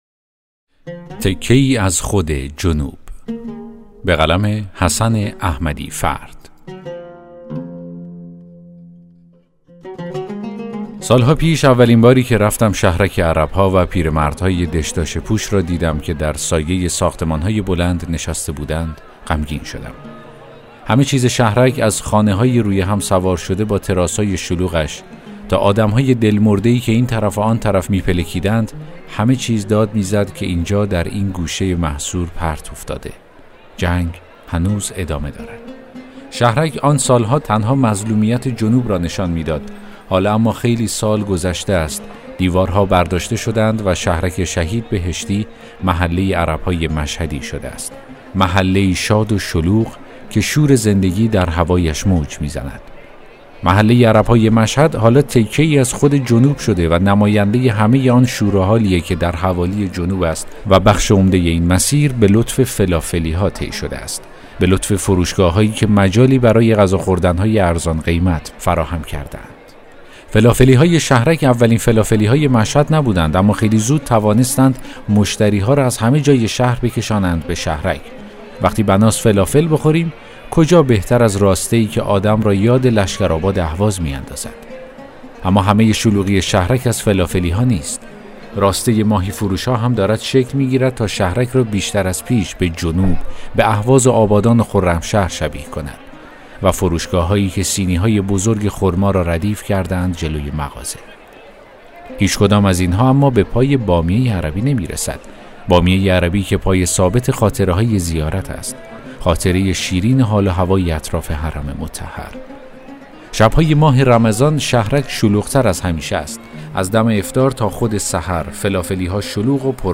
داستان صوتی: تکه‌ای از خود جنوب